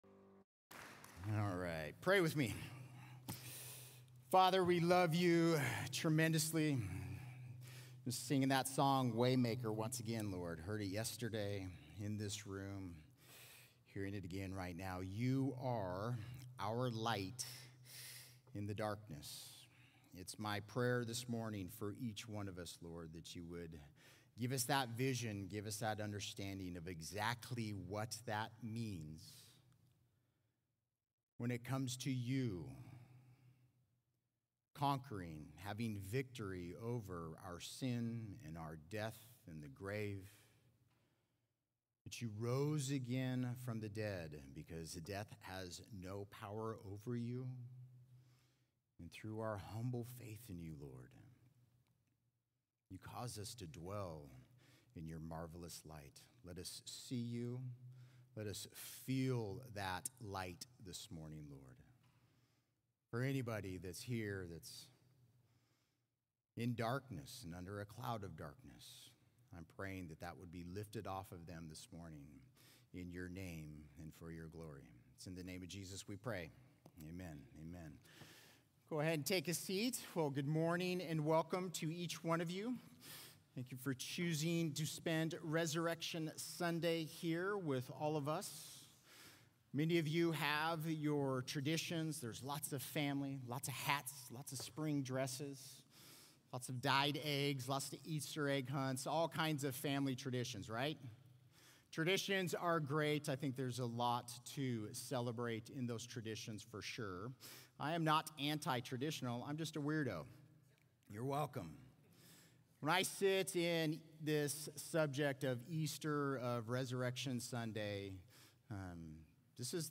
Similar to Sermons - Calvary Chapel Alpharetta